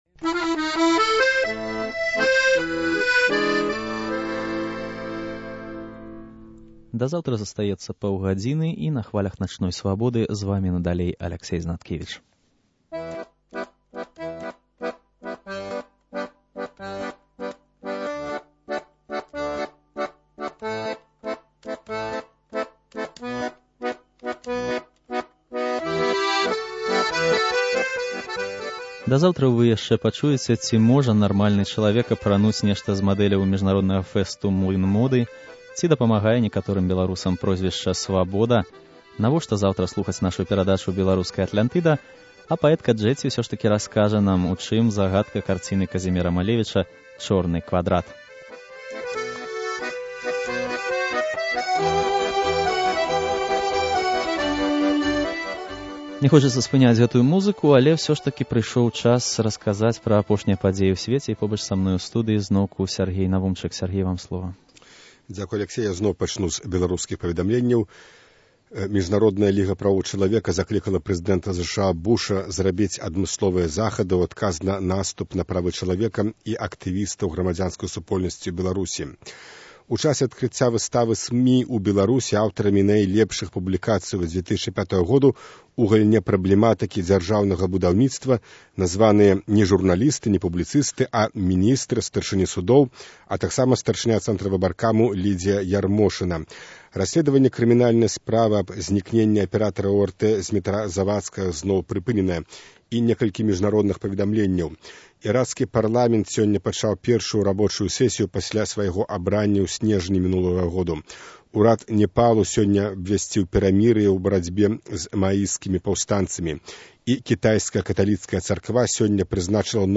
Аўдыё mp3 (7.2 Мэгабайт) Real Audio (4.5 Мэгабайт) * Ці дапамагае некаторым беларусам прозьвішча “Свабода”? * Рэпартаж зь міжнароднага фэсту “Млын моды”.